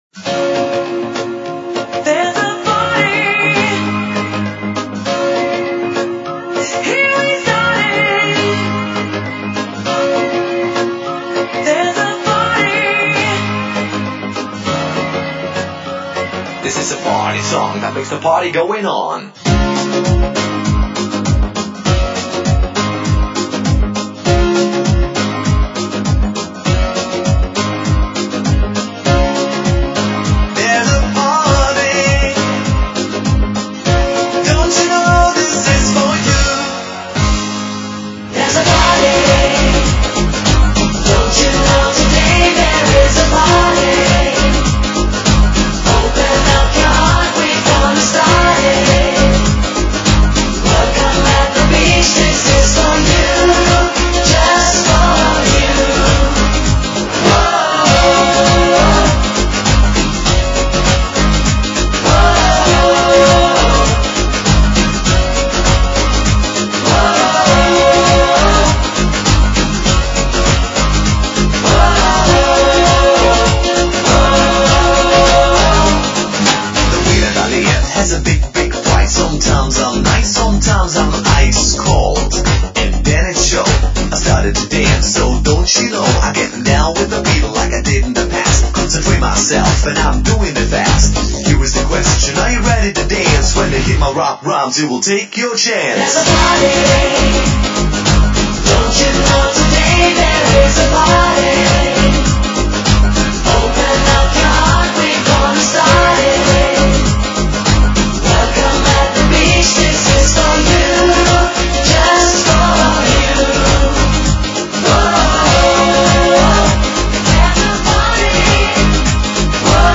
Dance Music Para Ouvir: Clik na Musica.